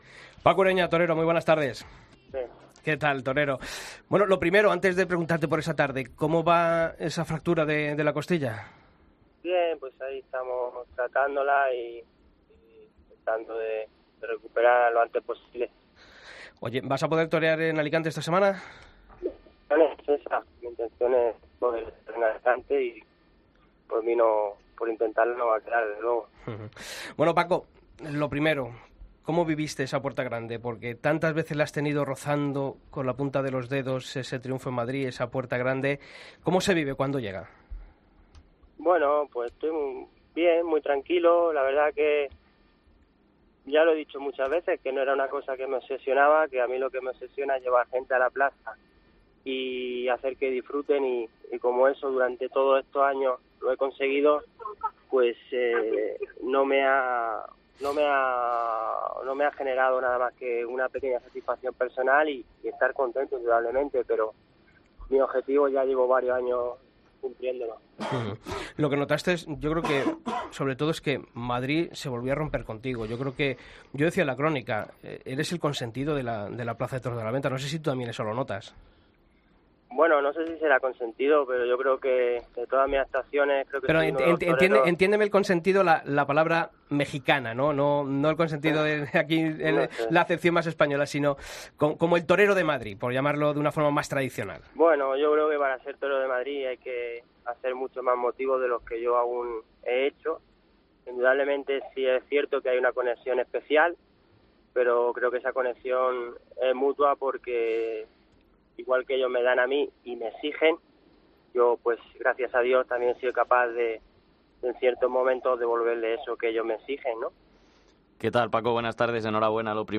Aún convaleciente de fractura sufrida el pasado sábado, Paco Ureña atendía esta semana la llamada de El Albero y confirmaba que se encontraba “ tratándo la costilla para poder recuperarme cuanto antes ”.